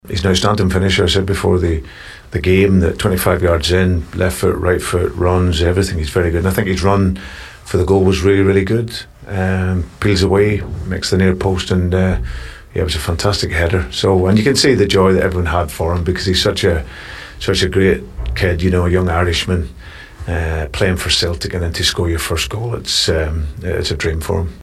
Rodgers says Kenny deserved his breakthrough moment…